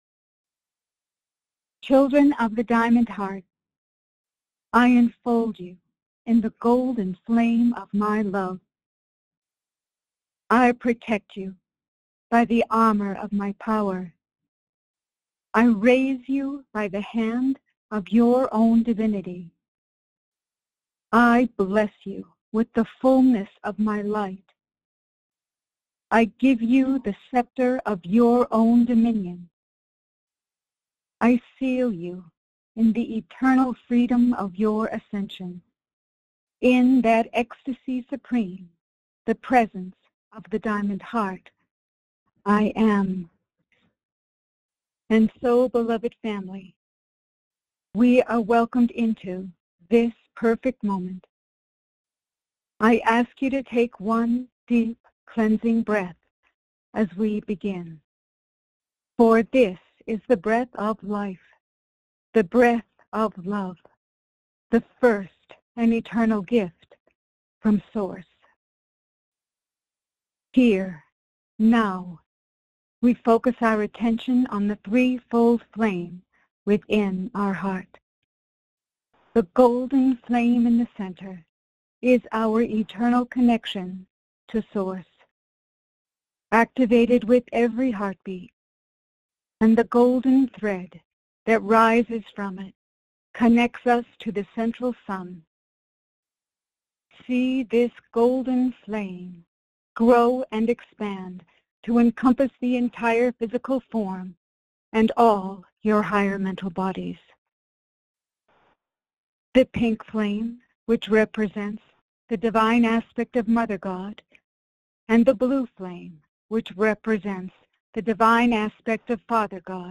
Join Lord Sananda in group meditation.